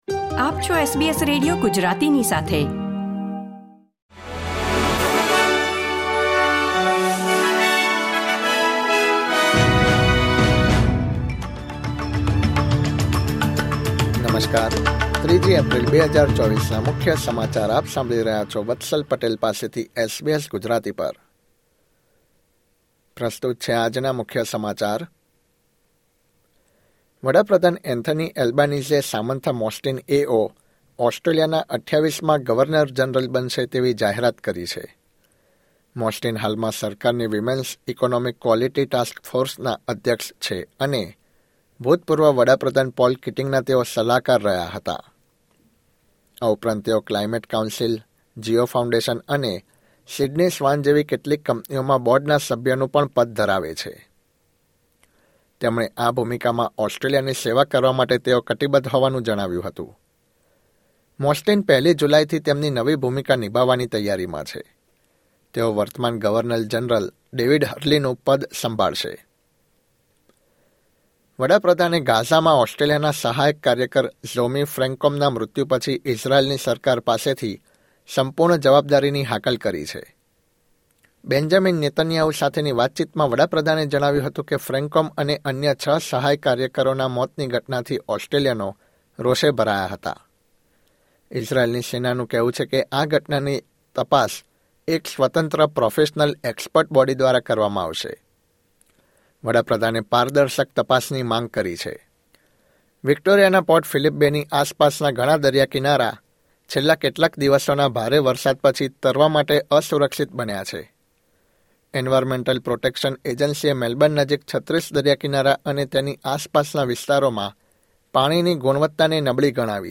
SBS Gujarati News Bulletin 3 April 2024